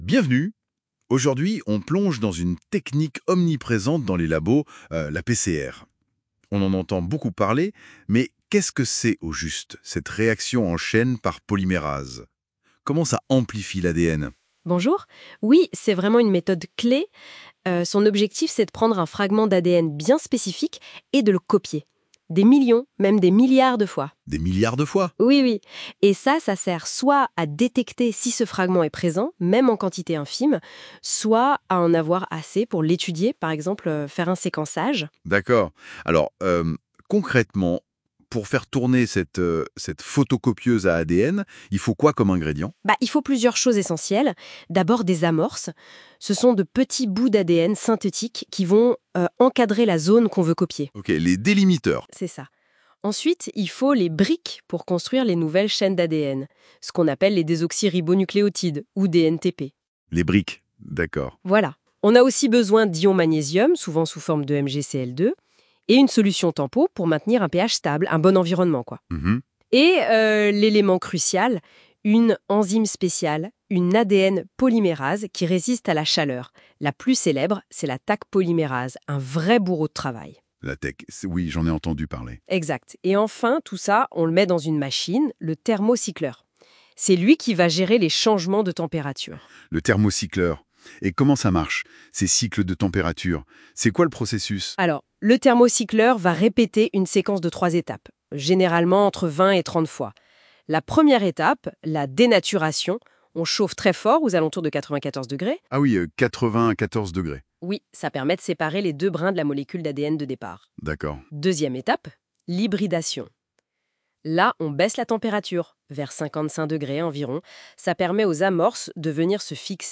Ce scénario est basé sur l’utilisation de NotebookLM pour générer à partir d’un ou plusieurs documents un Podcast vivant où deux interlocuteurs conversent. La présence de deux interlocuteurs (un homme et une femme) améliore la qualité de l’attention.